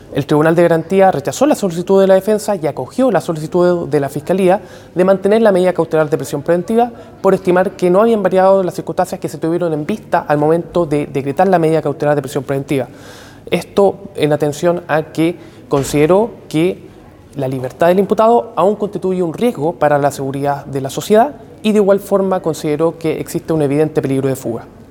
Tras ella, el fiscal Carlos Jorquera confirmó a La Radio que la magistrada consideró que la libertad de Ojeda sigue siendo “un riesgo para la seguridad de la sociedad, y de igual forma consideró que existe un evidente peligro de fuga”.